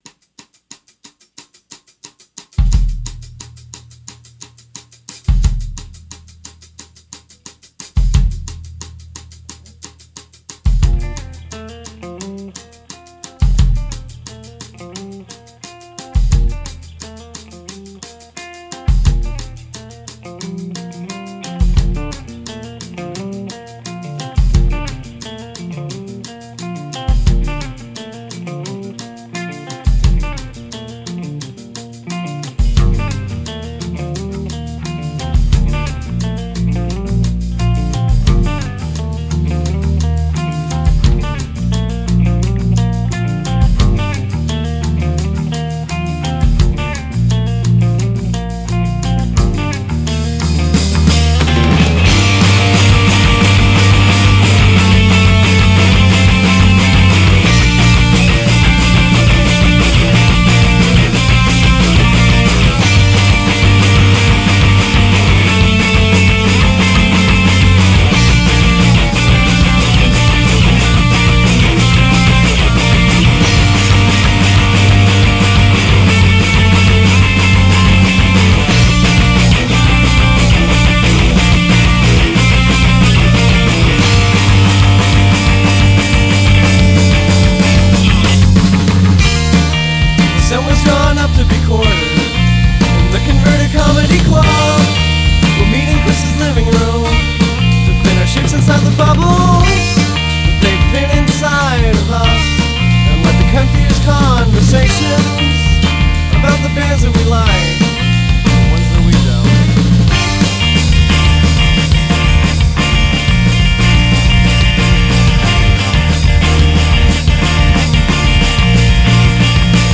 trombone, guitar, vocals, keyboards, aux percussion
bass, vocals, probably aux percussion